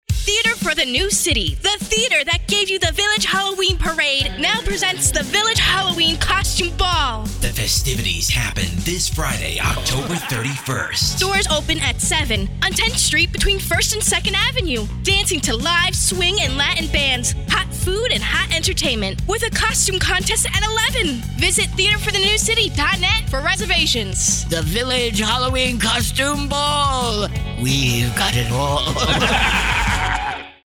Halloween spot from 1010 WINS: